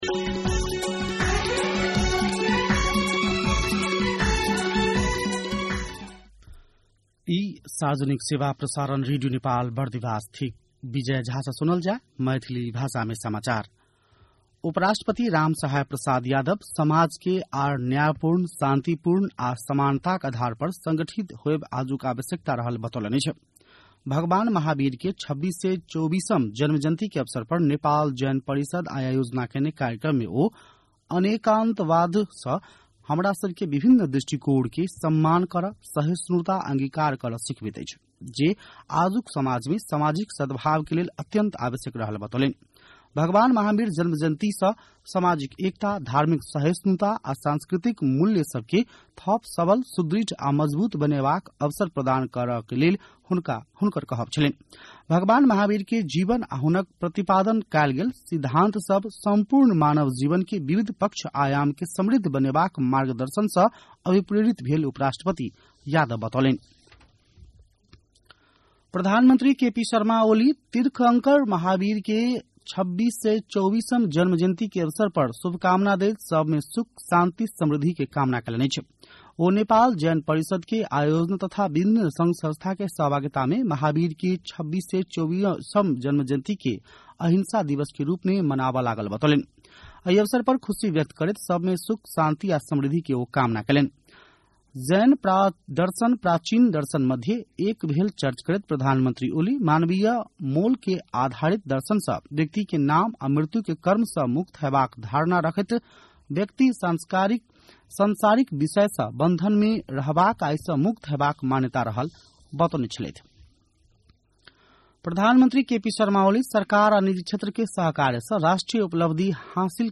मैथिली भाषामा समाचार : २८ चैत , २०८१